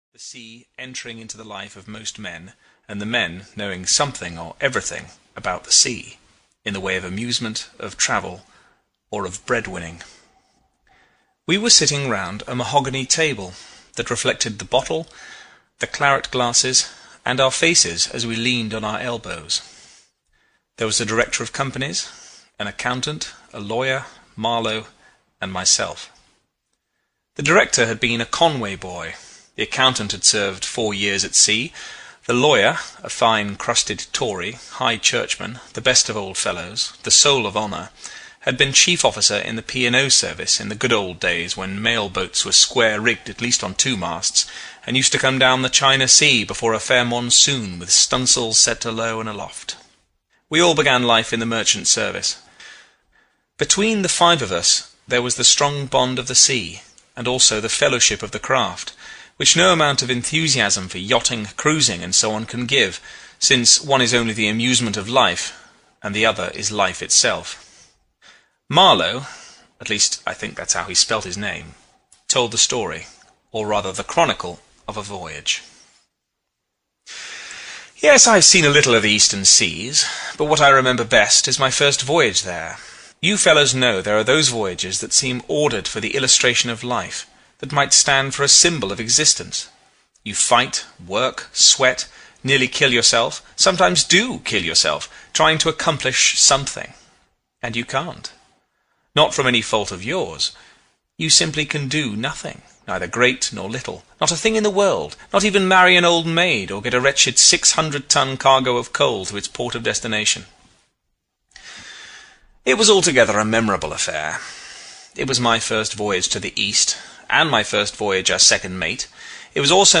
Youth, a Narrative (EN) audiokniha
Ukázka z knihy